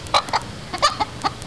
ukokkei.wav